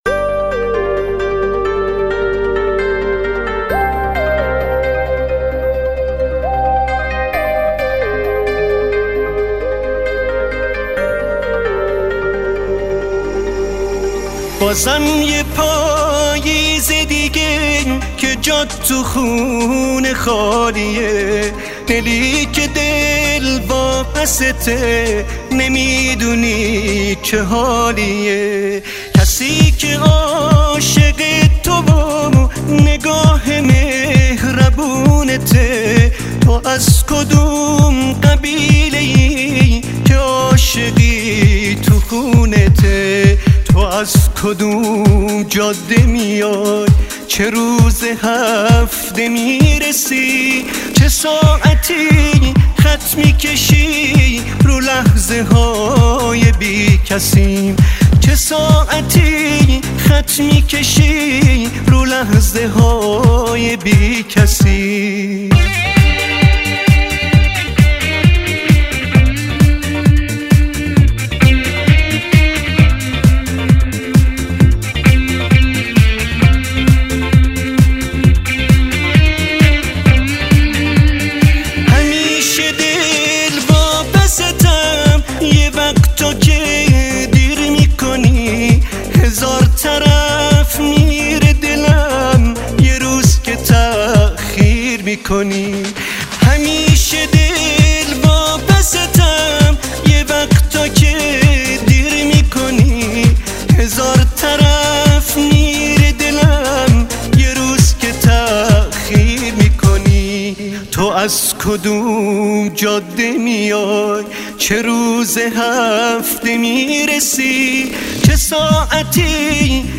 • پاپ
دسته : پاپ